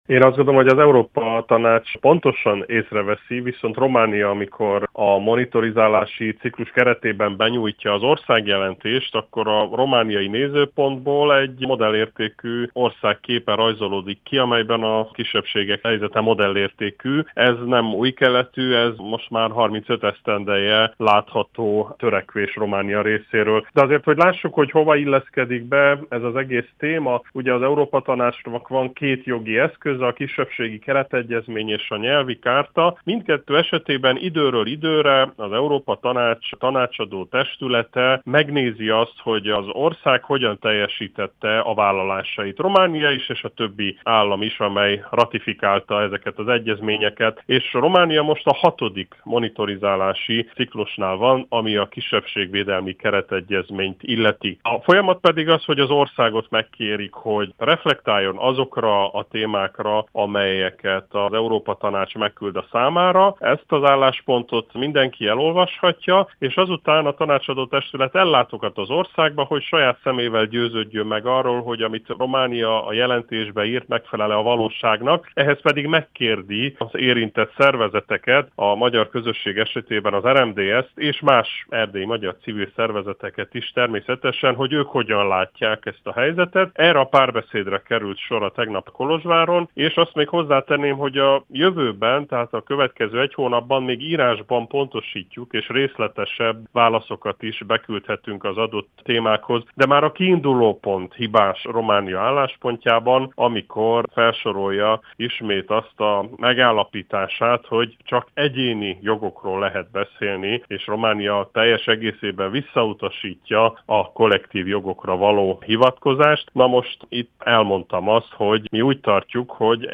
Milyen problémák vannak, mivel lehetne ezeken segíteni és milyen hatása lehet az Európa Tanácsnak – kérdeztük Vincze Loránt EP-képviselőt.